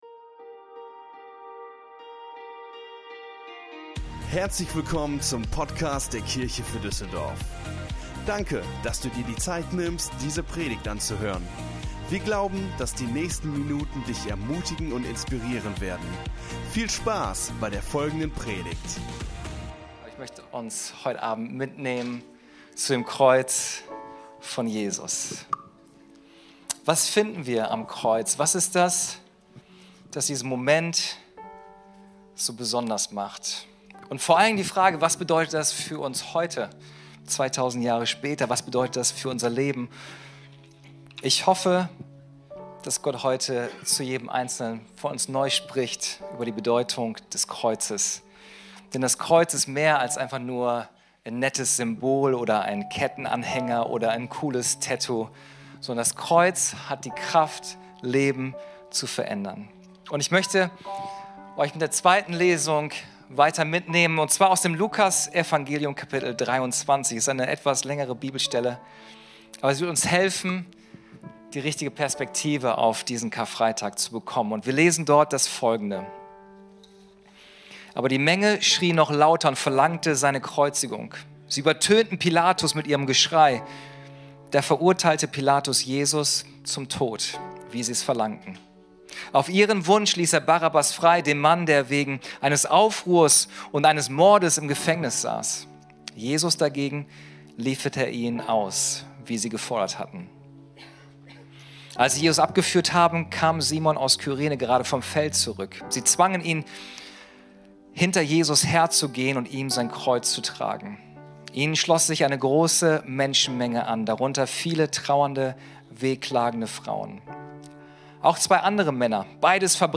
Karfreitag - Gottesdienst